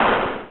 ViolasB2.wav